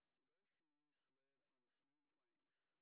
sp01_street_snr30.wav